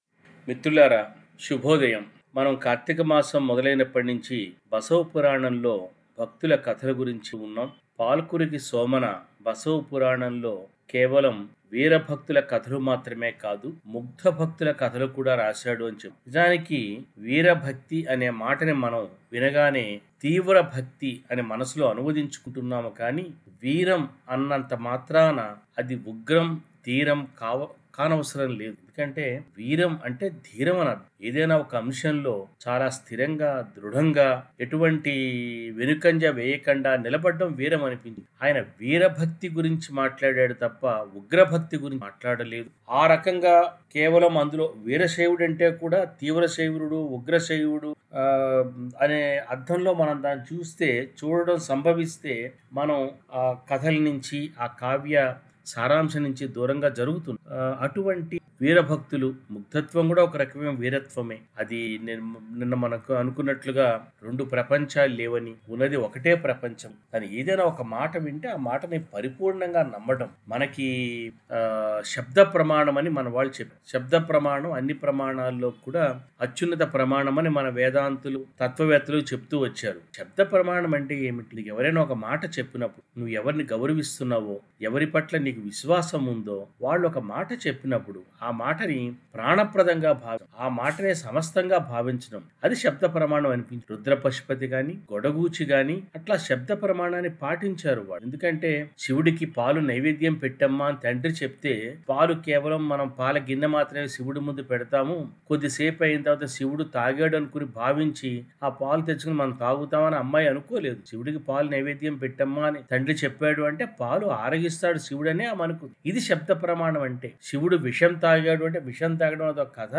బసవపురాణంలో ముగ్ధభక్తుల కథల గురించి చేస్తూ వచ్చిన ప్రసంగాలకు కొనసాగింపుగా ఇవాళ బసవణ్ణ భక్తి గురించిన ప్రసంగం. బసవణ్ణ భక్తి జ్ఞానభక్తి అనీ, శాంతభక్తి అనీ వ్యాఖ్యాతలు వివరించారు.